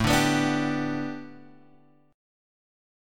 A 9th Suspended 4th
A9sus4 chord {5 5 x 4 3 3} chord